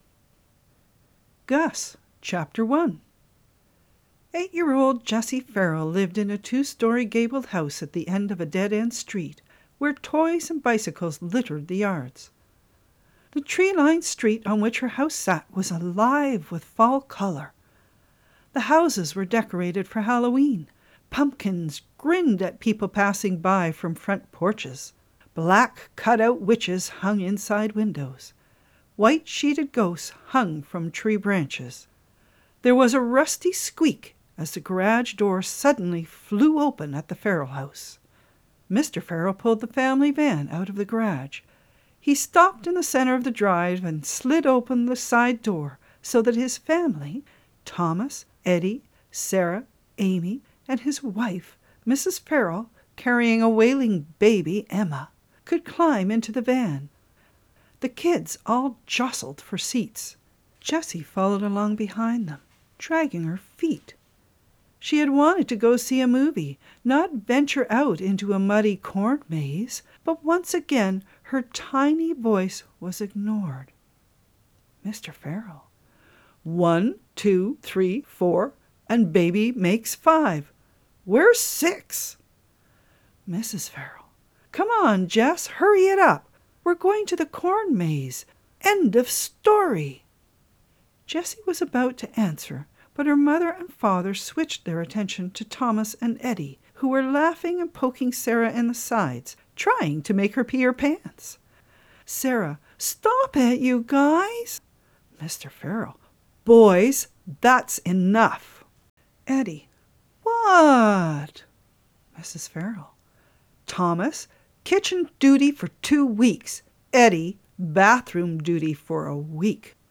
This is what started me reading these stories in the play format so that the younger and older children as well as those with learning disabilities could follow along.